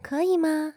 人声采集素材/人物休闲/请求.wav